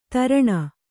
♪ taraṇa